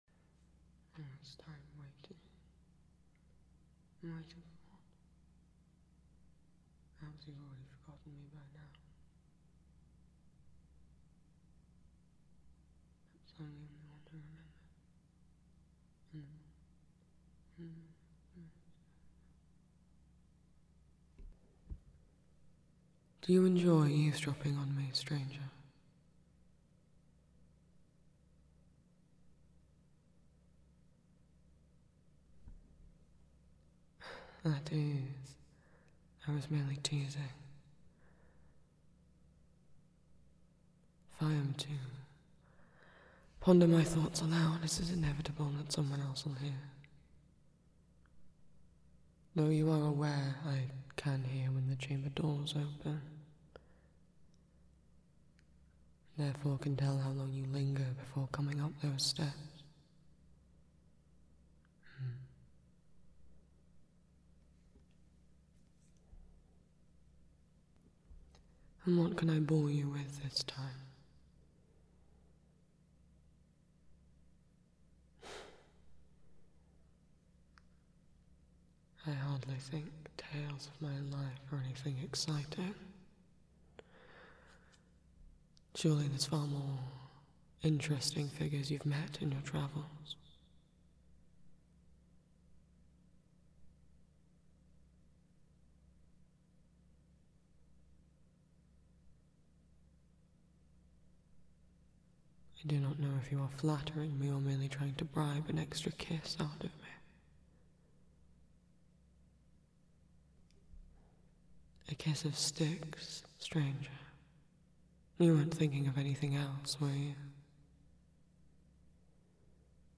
[M4M]
While no one specifically requested this audio this month, Patroclus has been requested many times over the years and I hesitated as I don't think I can get his voice to sound any different from Achilles, but I tried my best.